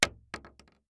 shotgun_wood_3.ogg